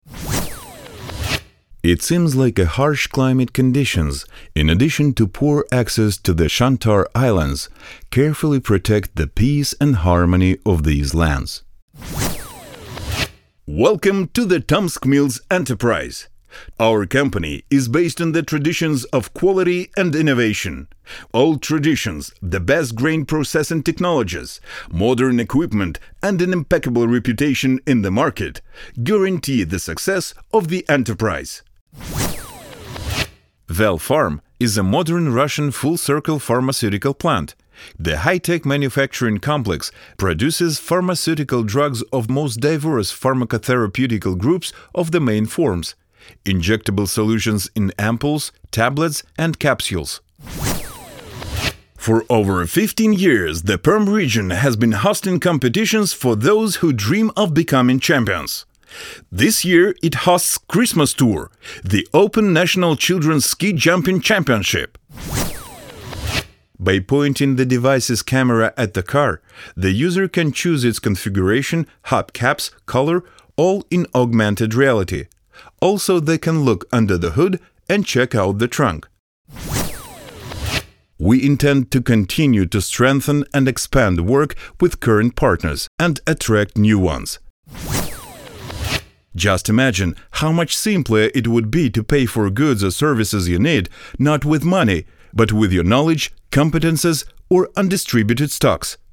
Баритон.
Информационная; нейтральная; игровая; экспрессивная подача.
Тракт: Микрофон: Neumann TLM 103 Преамп: DBX 376 tube Карта: ESI MAYA44